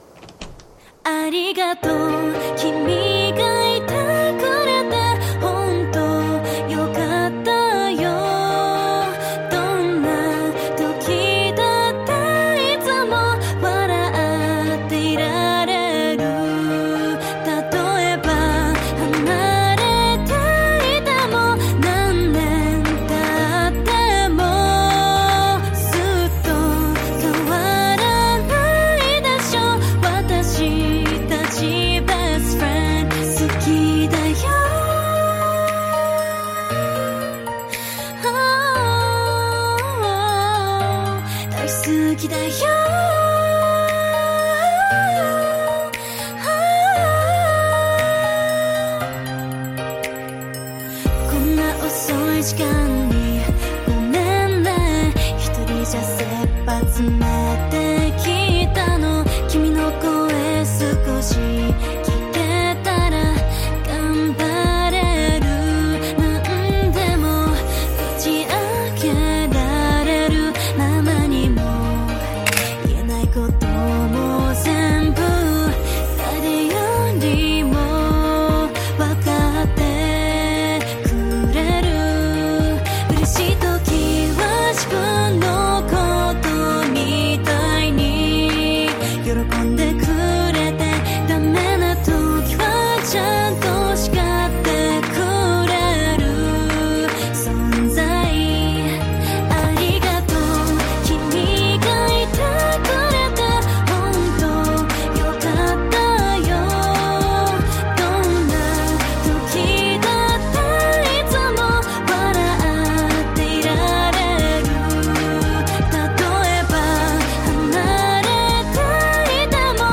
KPop Song
Label Ballad